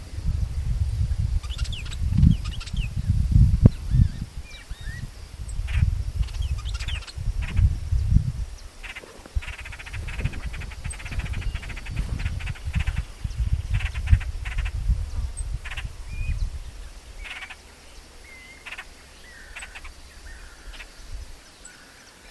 Brown-and-yellow Marshbird (Pseudoleistes virescens)
Dos ejemplares vocalizando.
Location or protected area: Parque Costero del Sur
Condition: Wild
Certainty: Observed, Recorded vocal
Pecho-Amarillo-Comun.mp3